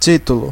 Ääntäminen
IPA: [titʁ]